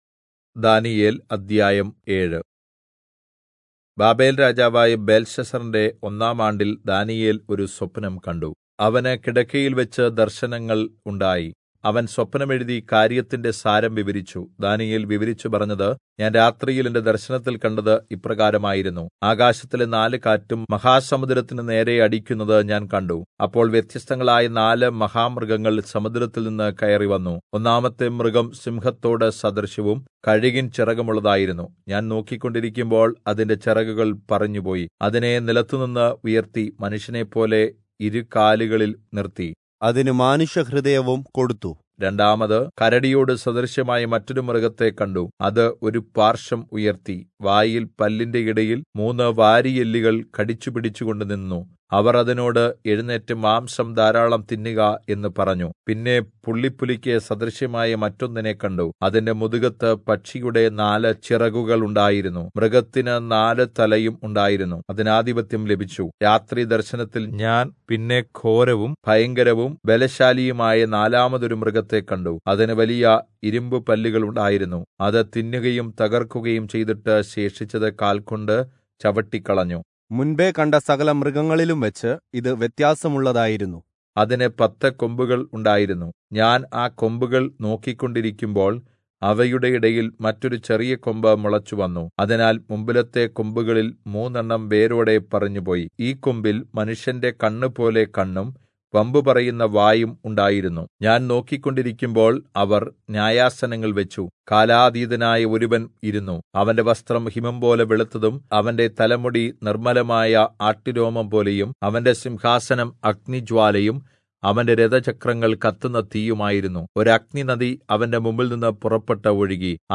Malayalam Audio Bible - Daniel 10 in Irvml bible version